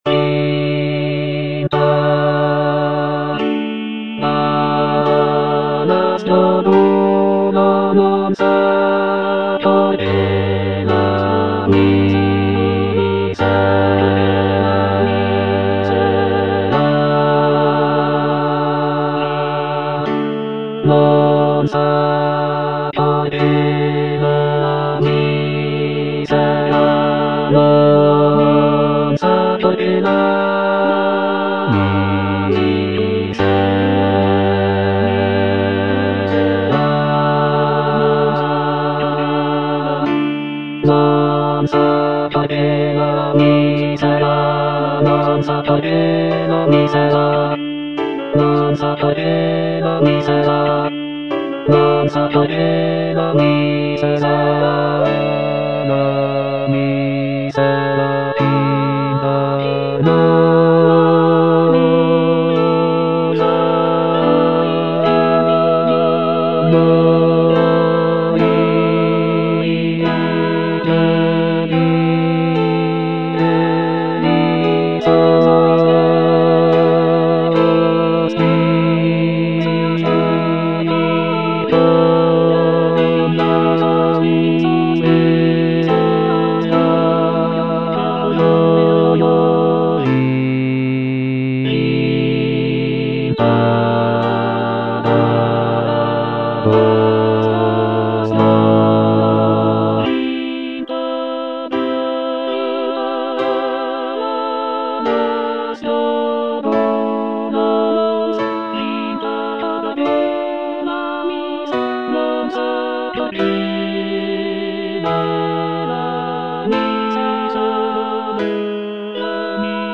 Bass (Emphasised voice and other voices) Ads stop